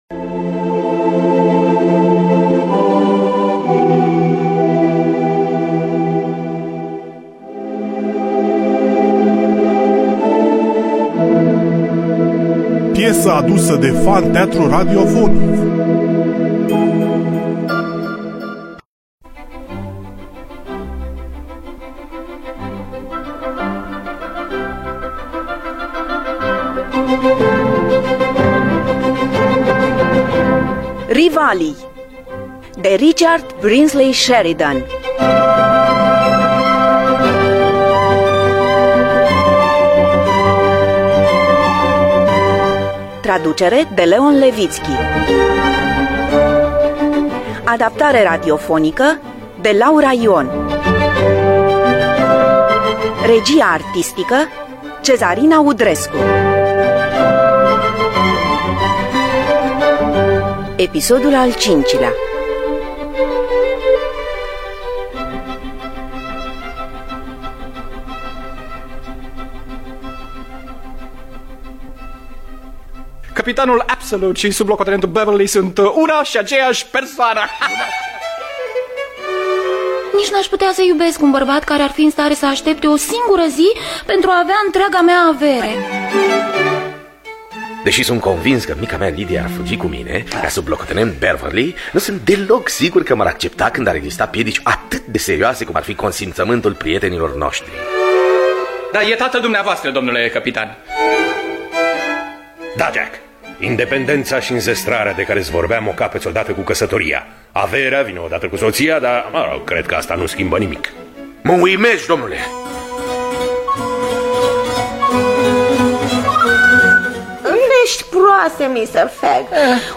Richard Brinsley Sheridan – Rivalii (2003) – Episodul 5 – Teatru Radiofonic Online